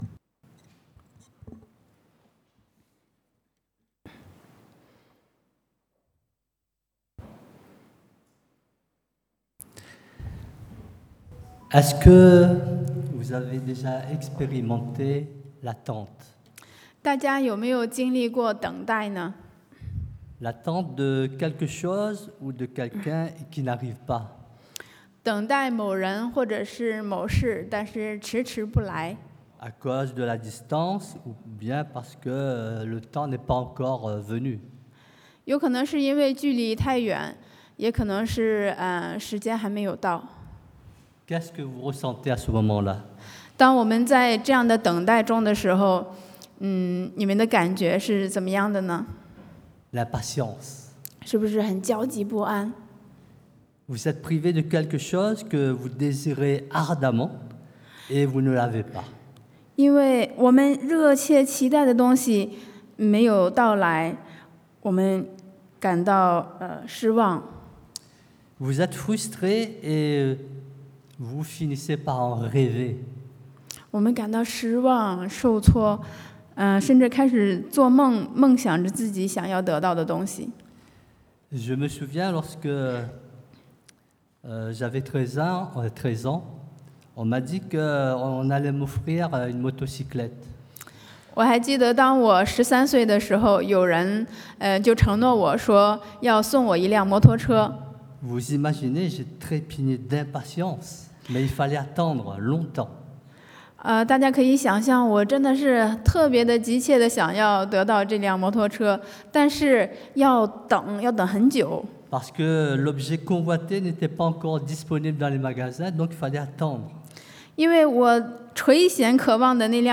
Sur la montagne, à la rencontre de Dieu 在山上，遇见神 – Culte du dimanche
Passage: Marc 马可福音 9 :2-13 Type De Service: Predication du dimanche